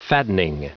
Prononciation du mot fattening en anglais (fichier audio)
Prononciation du mot : fattening